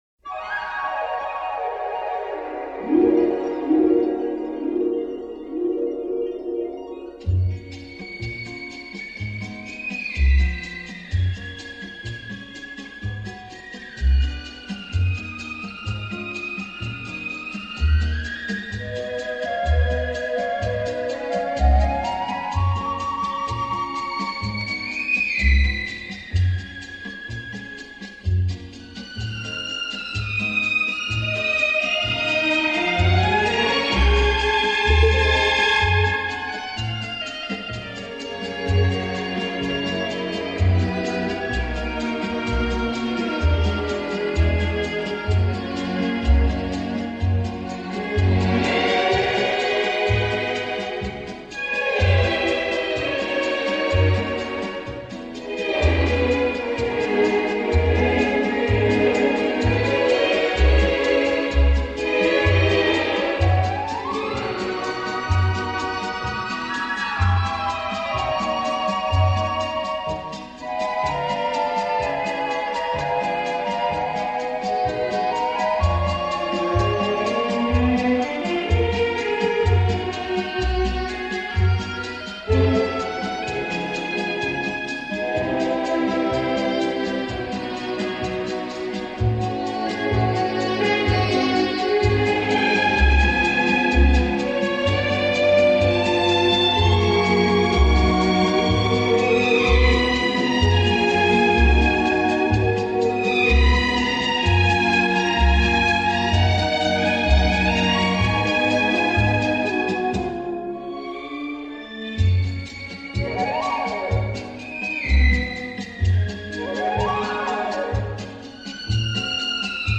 Genre: Classical
Style: Neo-Romantic, Easy Listening